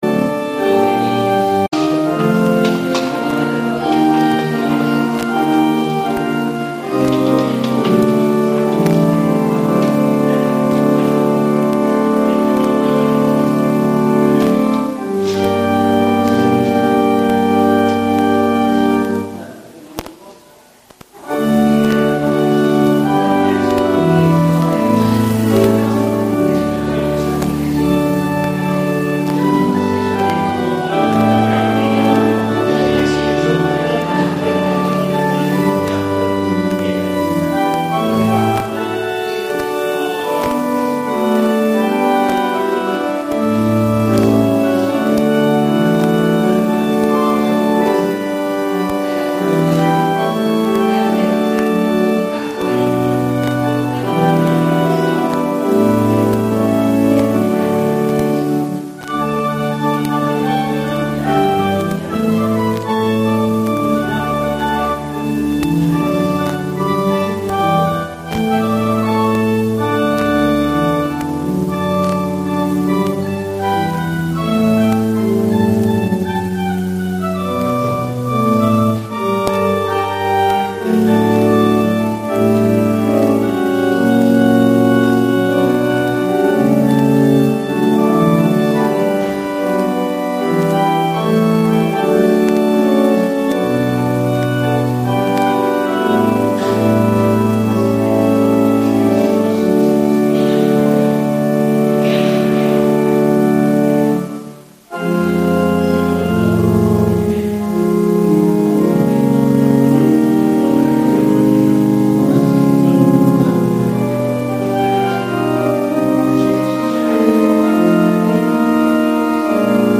Onderhoud kerkhof Dienst in Drimmelen Kerkdienst terug luisteren